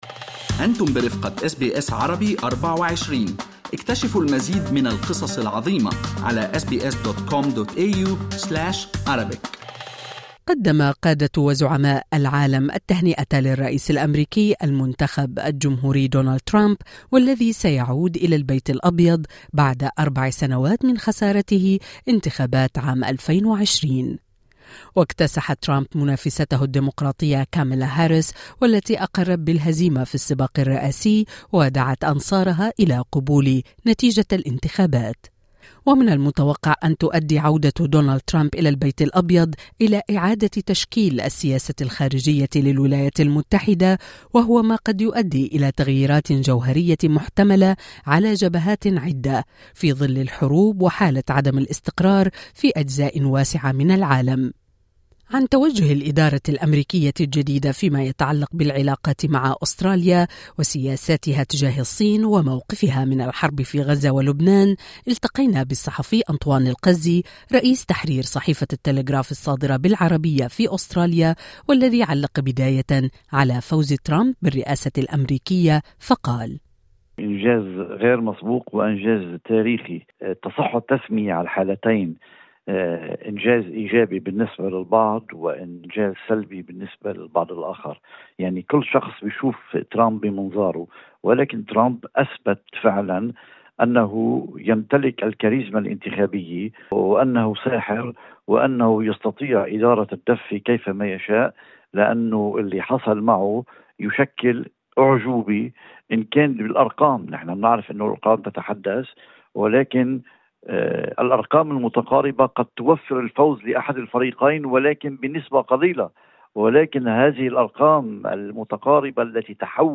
محلل سياسي يجيب